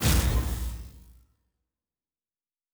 pgs/Assets/Audio/Sci-Fi Sounds/Interface/Complex Interface 1.wav at 7452e70b8c5ad2f7daae623e1a952eb18c9caab4
Complex Interface 1.wav